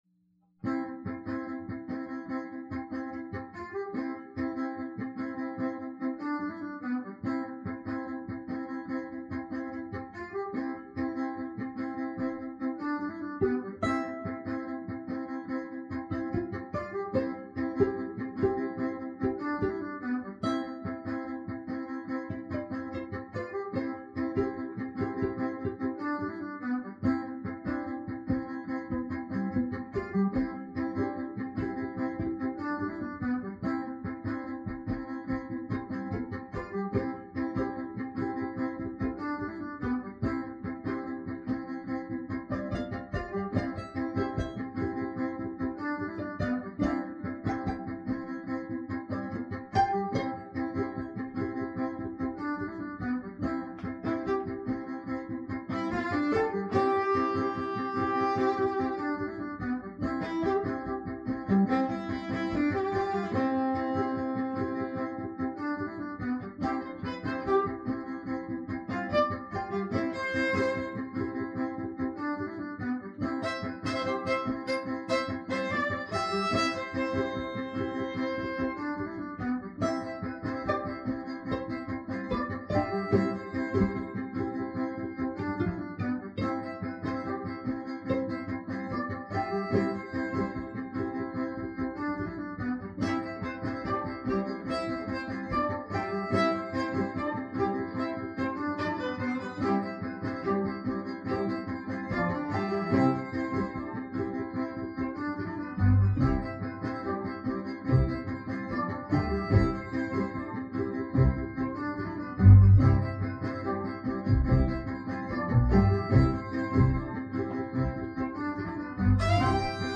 Classical / Loop Layer Effects